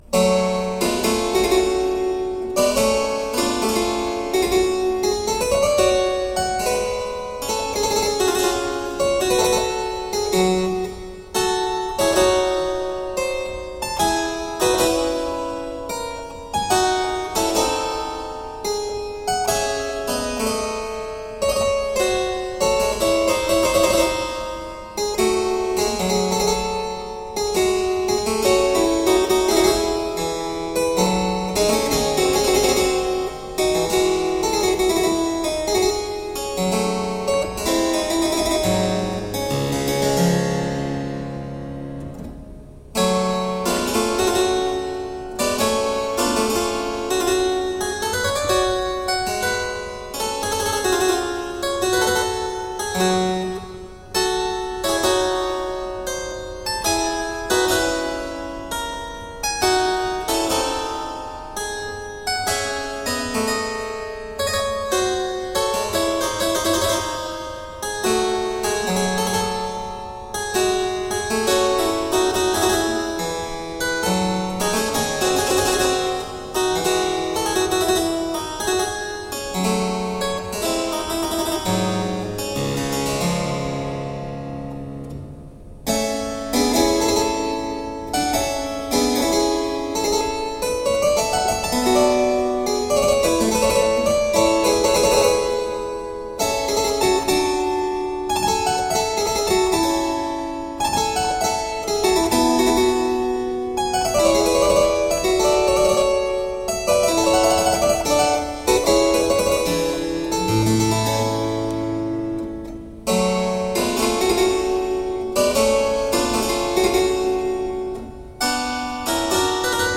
Crisp, dynamic harpsichord.